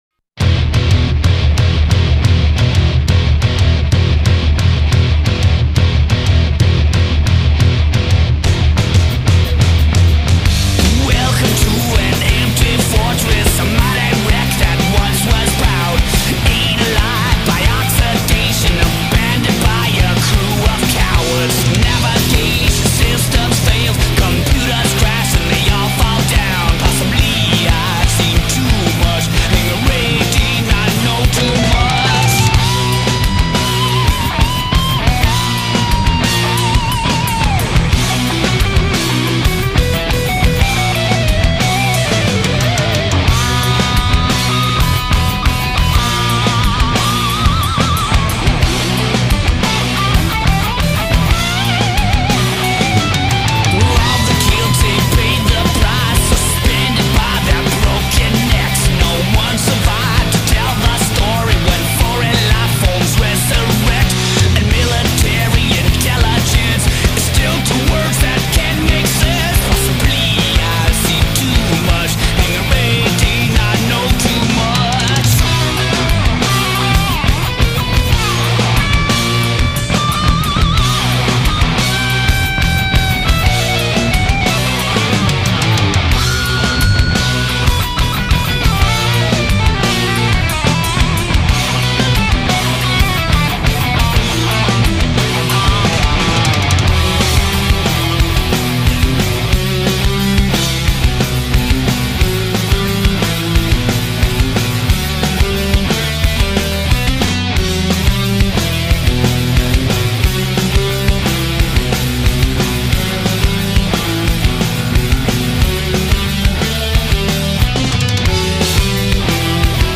метал-группы